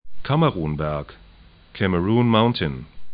'kaməru:n-bɛrk